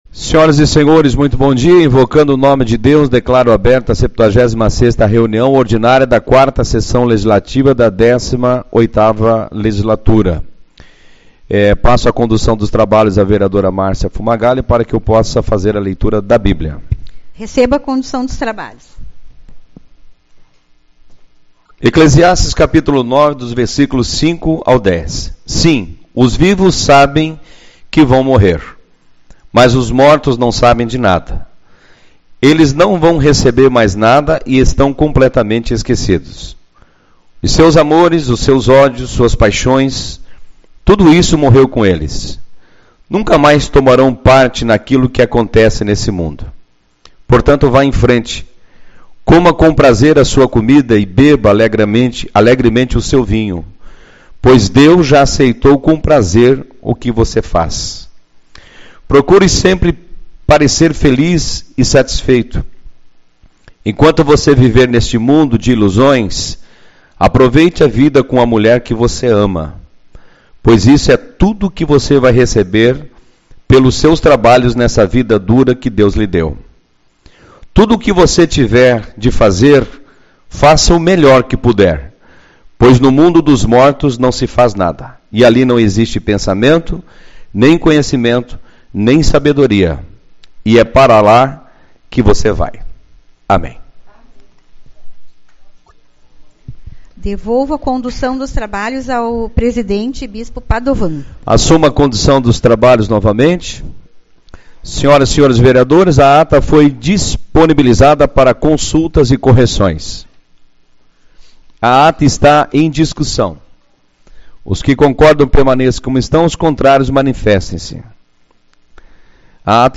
14/11 - Reunião Ordinária